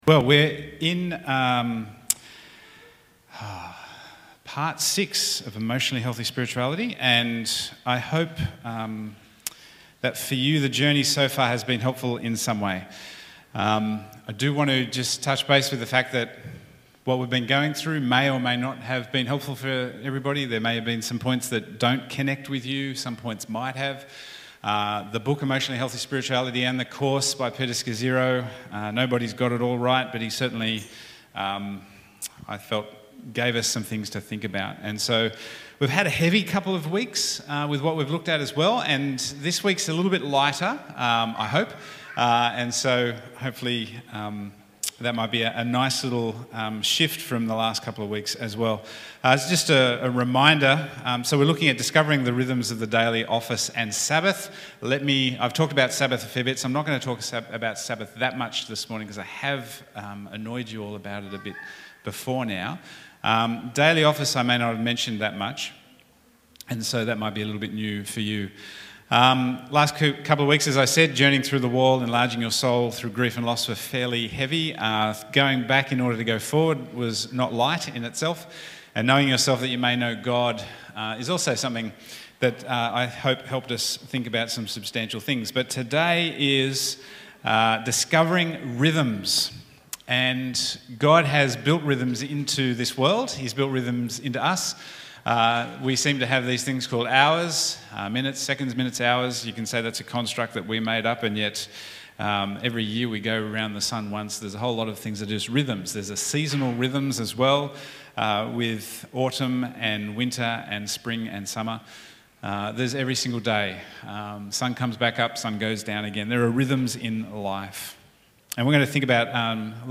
Bible Text: Acts 2:42-47 | Preacher